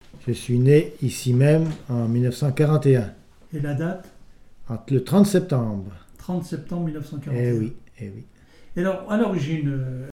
Il provient de Saint-Gervais.
Témoignage ( mémoire, activité,... )